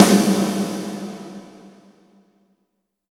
84 VERB SN-R.wav